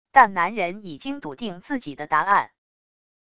但男人已经笃定自己的答案wav音频生成系统WAV Audio Player